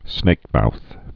(snākmouth)